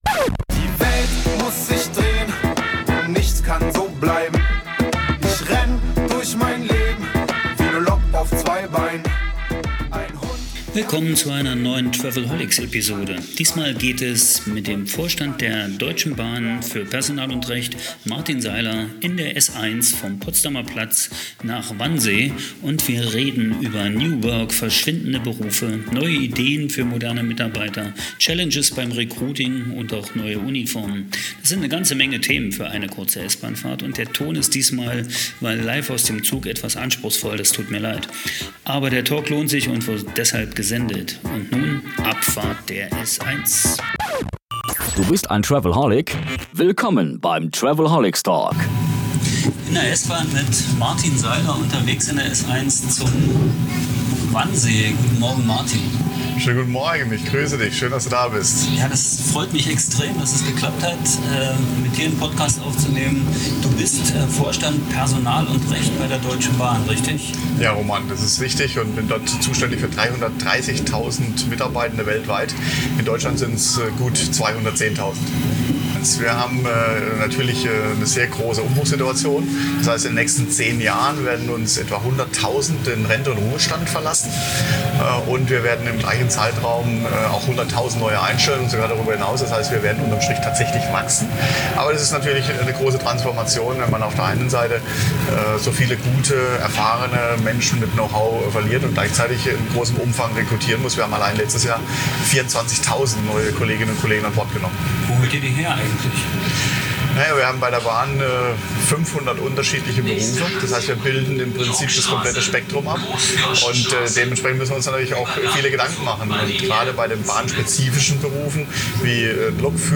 Vom Potsdamer Platz zum Wannsee mit der S1.
Rasant und spannend geht es um Zukunftsthemen und Initiativen, um neues Arbeiten, neue Uniformen und die neuen Herausforderungen beim Recruiting und Onboarding. Die S-Bahn fährt ziemlich genau 25 Minuten und die Umgebung ist nicht gerade leise. Das macht das Hören dieser Folge bestimmt etwas anstregend.